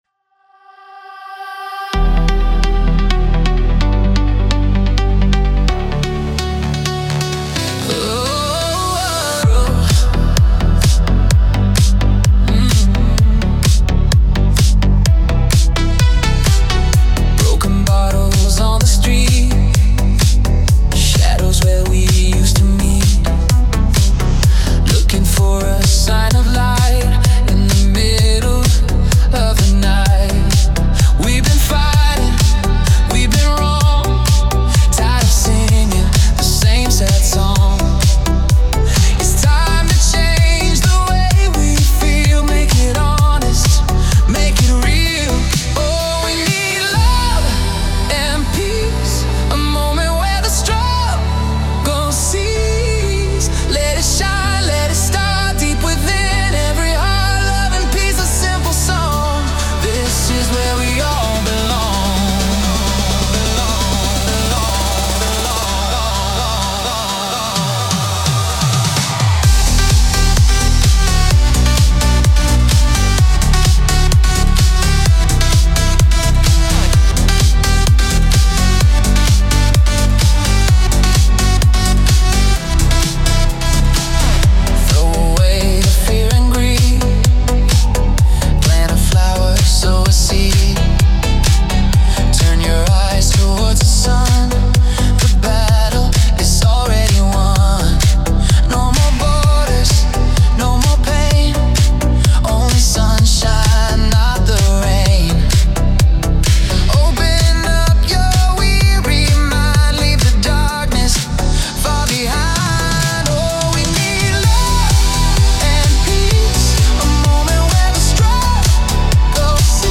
Pop - 3:47 Min.